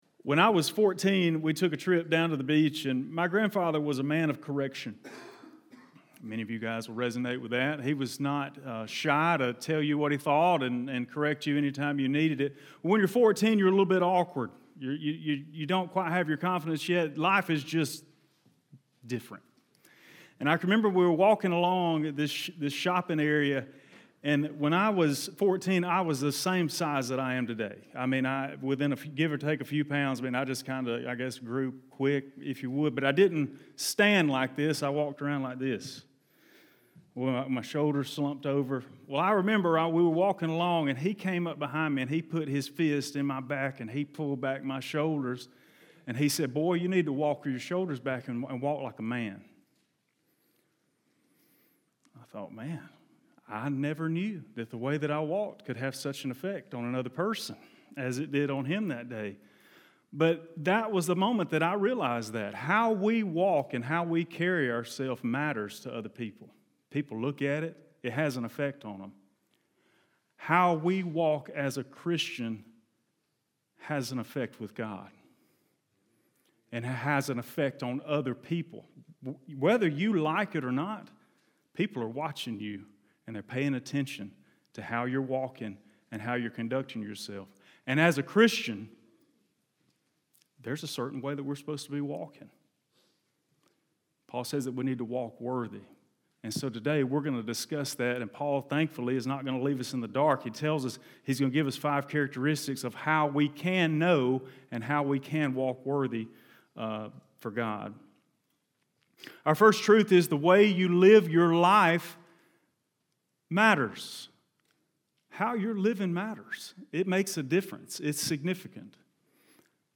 Blount Springs Baptist Church Sermons